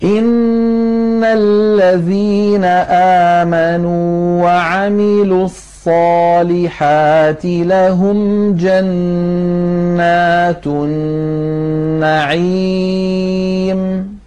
Non åtföljs av en ghunnah från Näspassagen ( khaishoom - الخَيشوم ).